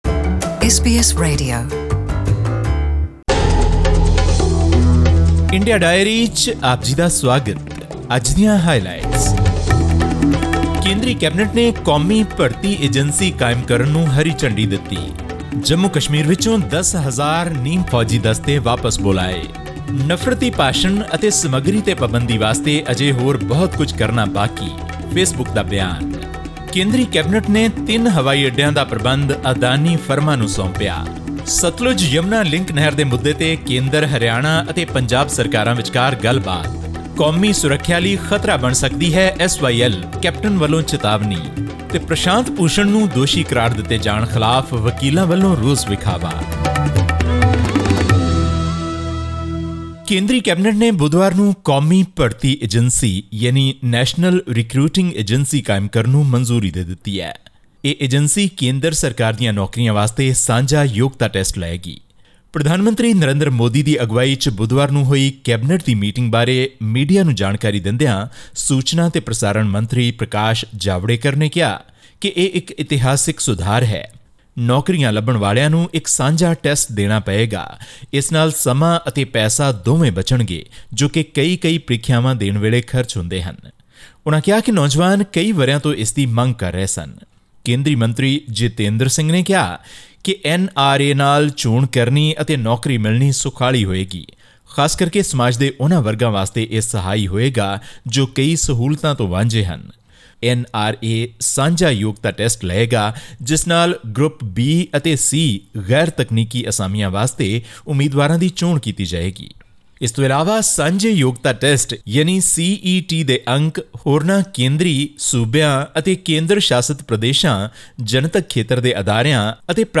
This and more in our weekly newswrap from India.